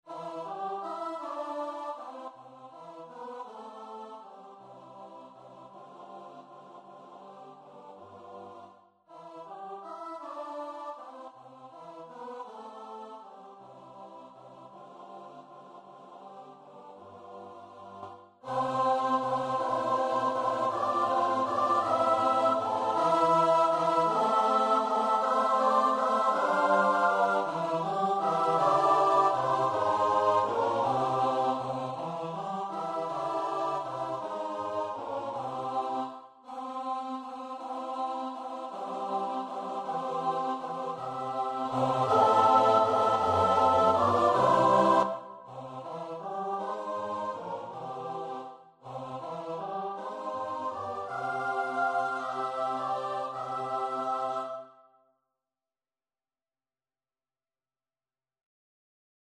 Общее пение Смешанный хор